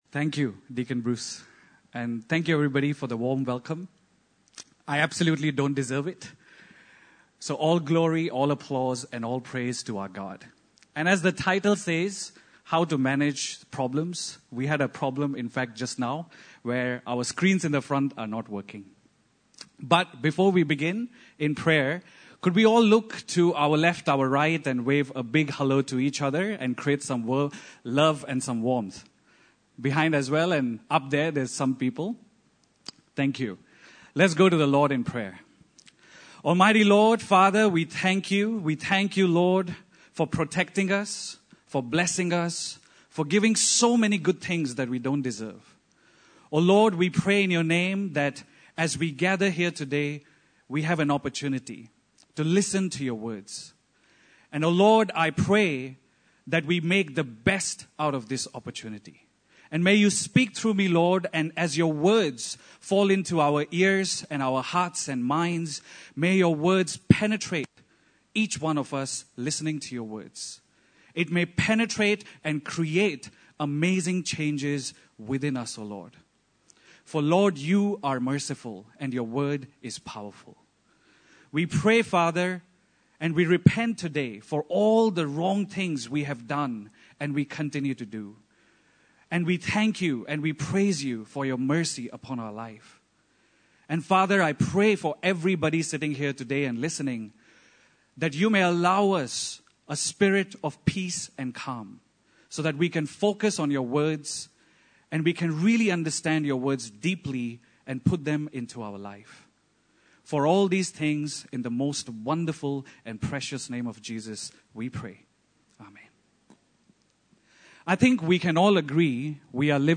Nehemiah 1:1-11 Service Type: Sunday Service